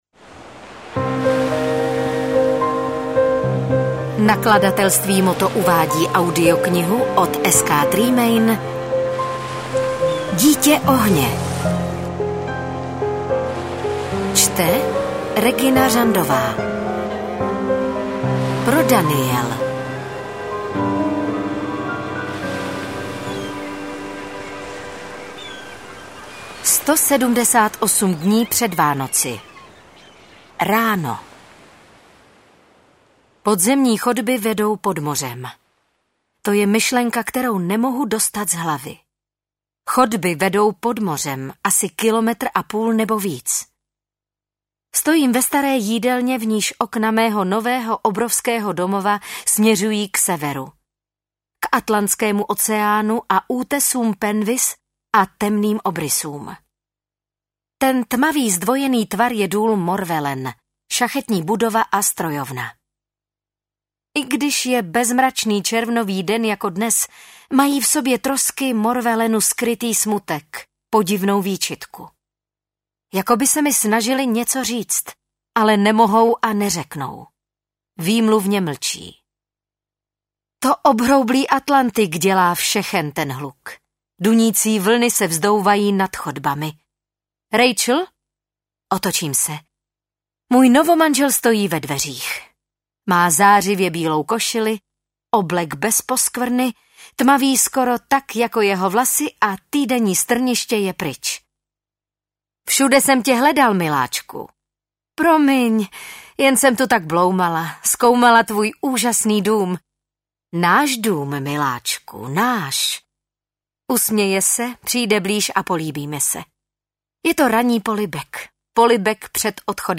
Interpret:  Regina Řandová
AudioKniha ke stažení, 47 x mp3, délka 11 hod. 25 min., velikost 628,8 MB, česky